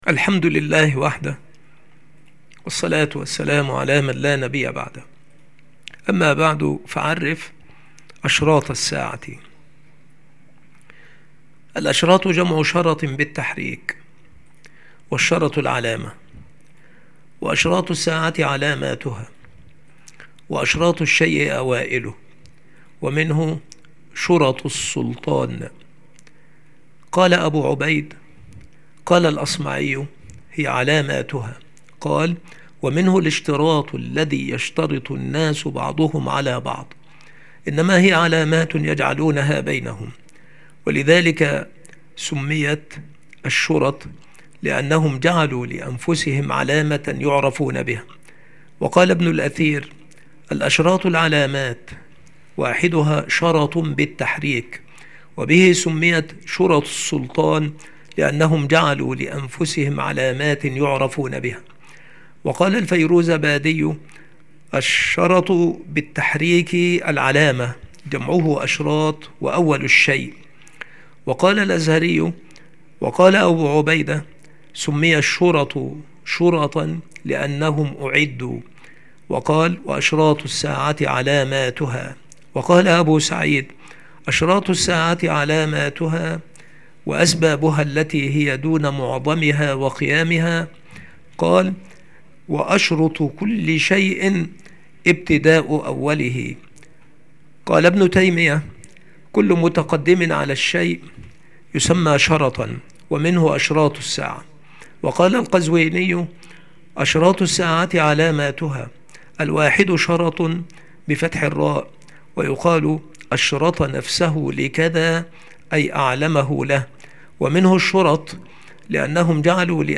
المحاضرة
مكان إلقاء هذه المحاضرة المكتبة - سبك الأحد - أشمون - محافظة المنوفية - مصر